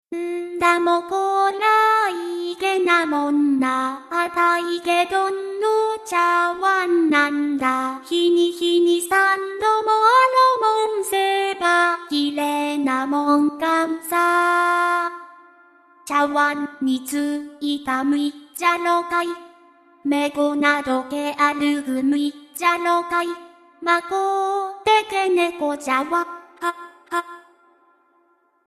鹿児島民謡「ちゃわんむしの歌」。鹿児島県民なら誰でも歌えるという妙な普及率を誇る歌なんですが、県外の人には何言ってるのか解らないという個性ありすぎな歌です。…
とりあえずミク別冊やマニュアルをちらちら見ながら打ち込んで、ちょっとでも聞きやすくならんだろうかとエフェクトもちょいちょいやってみて、なんとかこうなってみました。